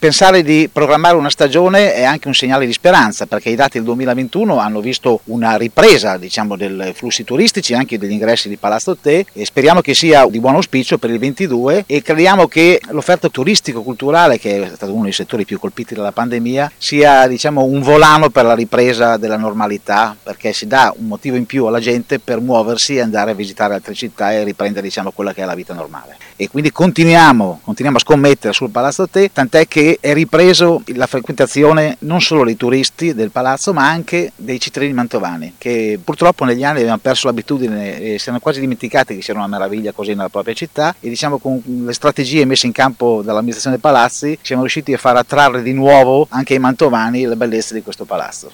Il vicesindaco di Mantova, Giovanni Buvoli:
Giovanni-Buvoli-Vicesidanco-e-assessore-al-Patrimonio-Sviluppo-Economico-e-Turismo-delcomune-di-Mantova.mp3